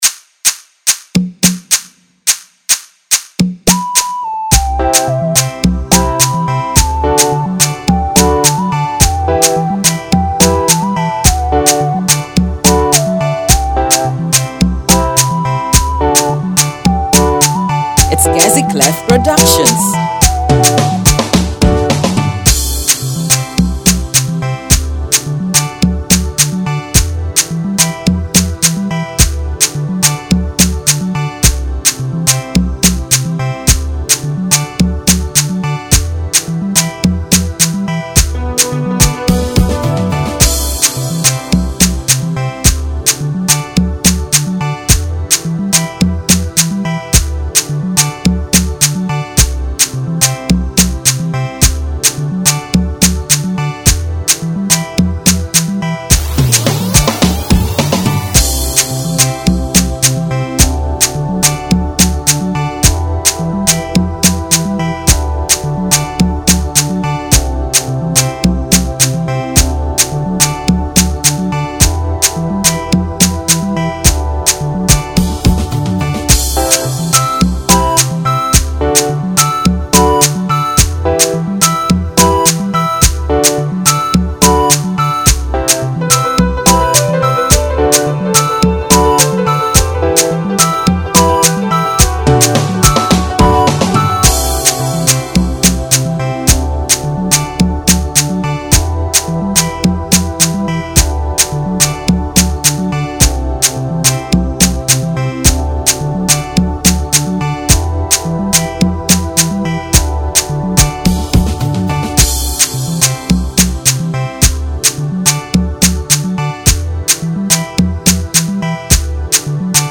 INSTRUMENTAL REMAKE